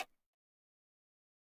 perc 10.wav